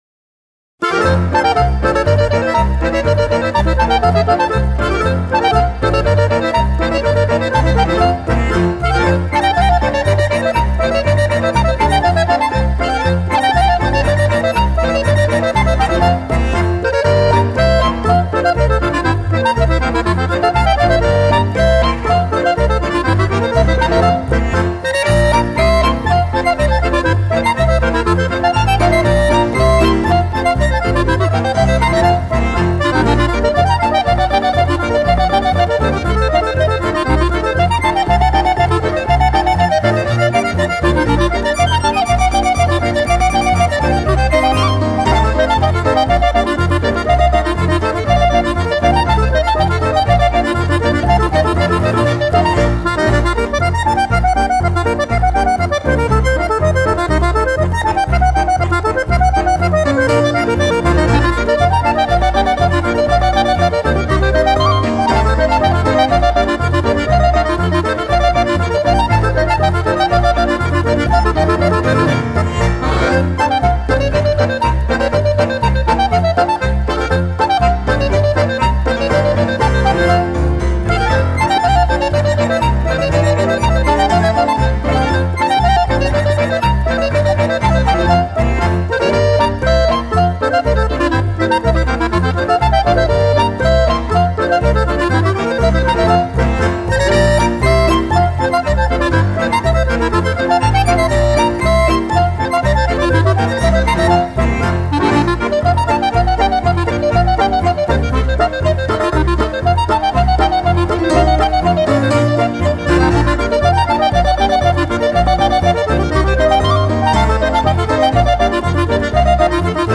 Clarinet and saxophone virtuoso